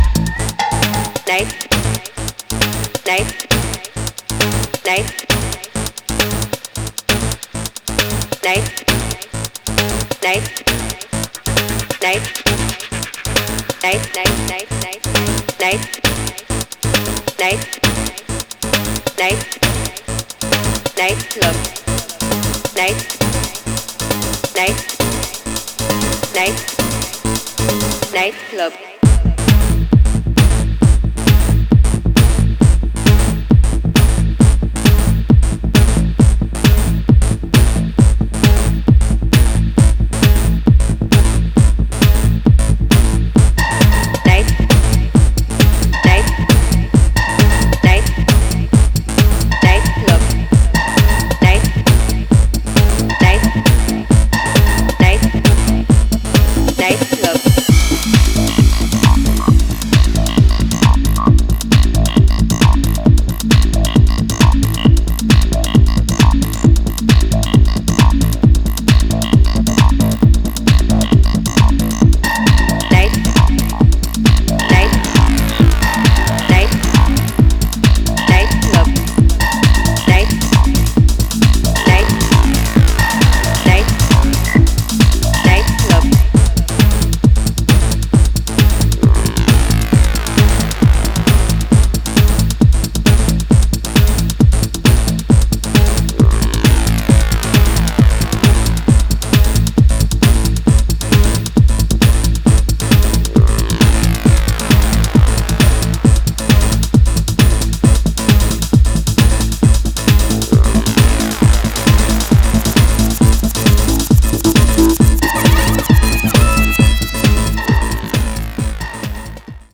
ダークなアシッドを基調にヒプノティックなハメのグルーヴでじっくりとビルドアップする